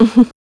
Ripine-Vox-Laugh-02.wav